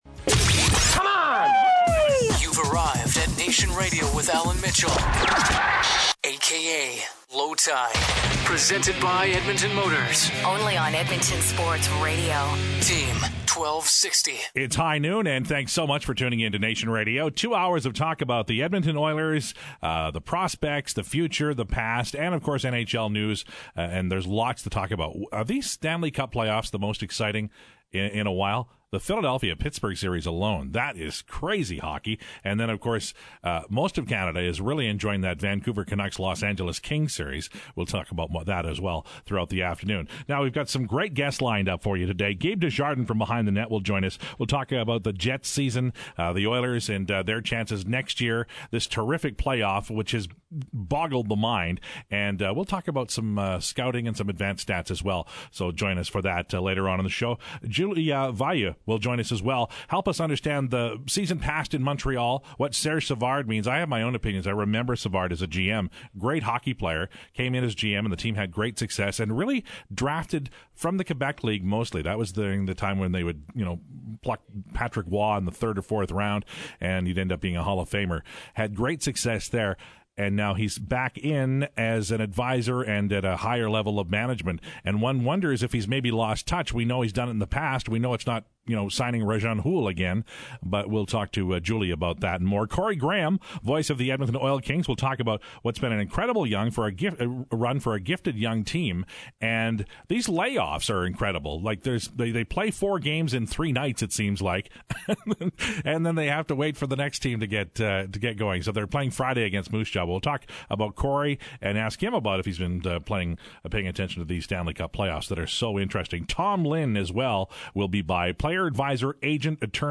Segment 2 If you missed it the first time, here is the Steve Tambellini press conference.